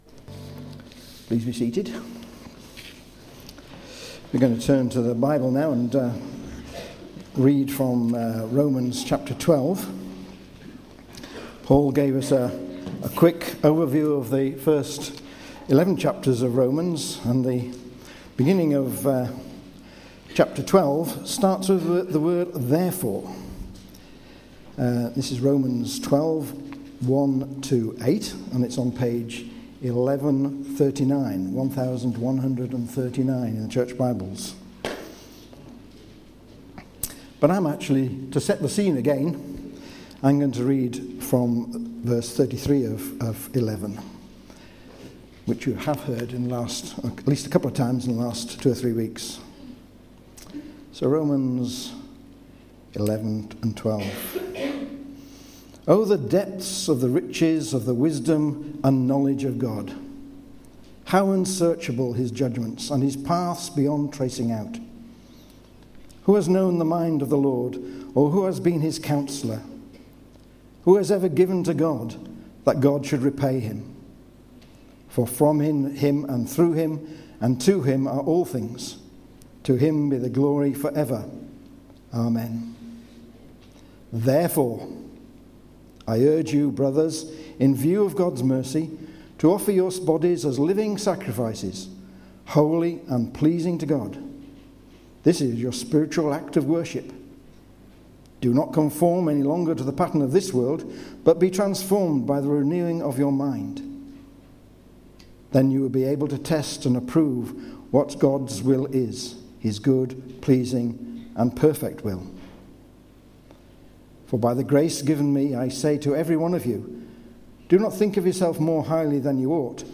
Media for Sunday Service on Sun 08th Feb 2015 10:00
Theme: Sermon